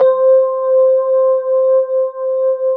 FEND1L  C4-R.wav